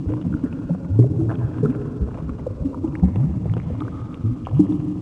BUBBLELP.WAV